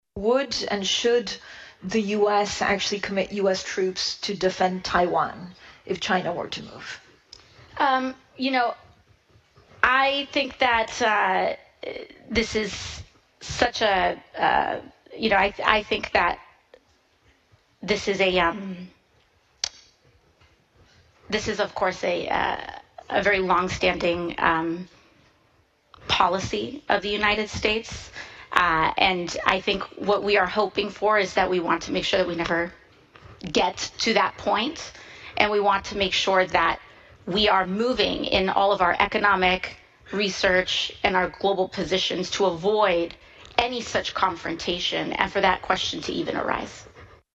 Alexandria Ocasio-Cortez gave a 40-second-long rambling response when asked Friday if the U.S. should send troops to protect Taiwan if China invaded the contested Asian island.